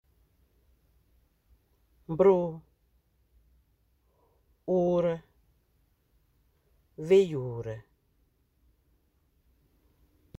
Accueil > Prononciation > uu > uu